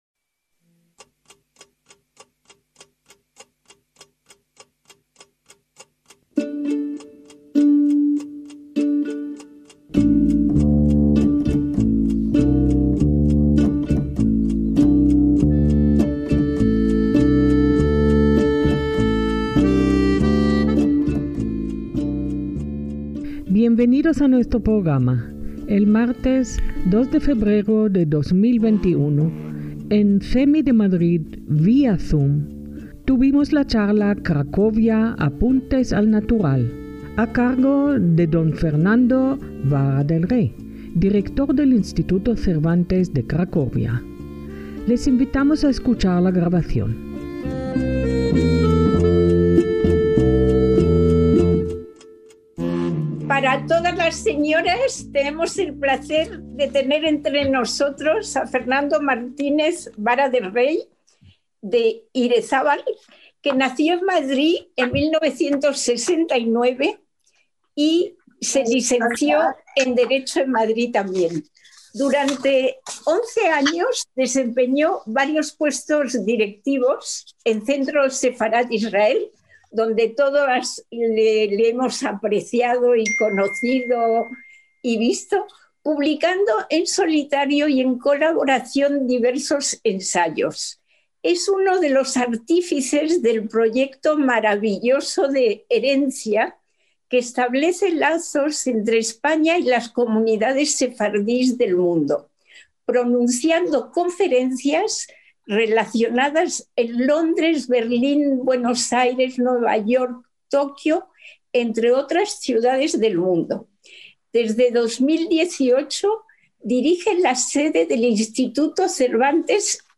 ACTOS "EN DIRECTO"